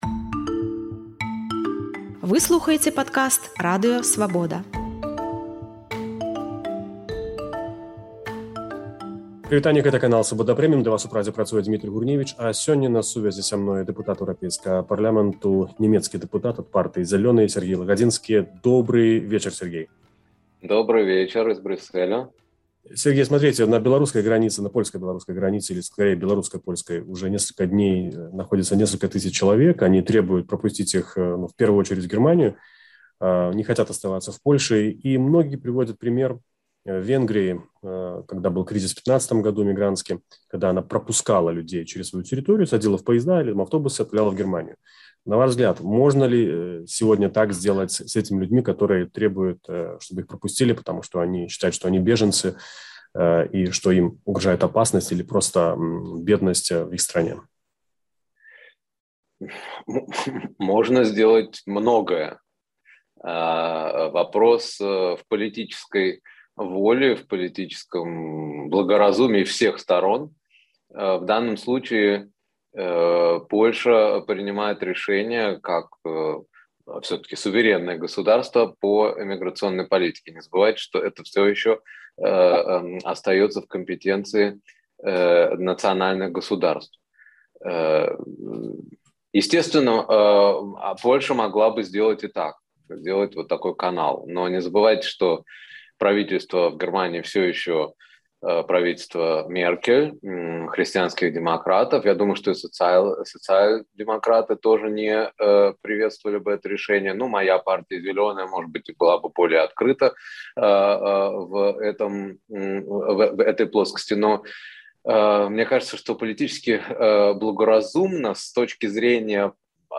Дэпутат Эўрапарлямэнту ад нямецкай партыі Зялёныя Сяргей Лагадзінскі расказвае ў інтэрвію Свабодзе, як можна вырашыць міграцыйны крызіс на мяжы Беларусі і ЭЗ, ці трэба Брусэлю дамаўляцца зь Менскам і чаму Лукашэнка "гуляе з агнём".